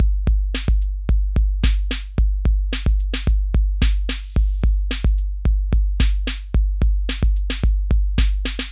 基本型阿维亚TR 808
标签： 110 bpm Electro Loops Drum Loops 1.47 MB wav Key : Unknown
声道立体声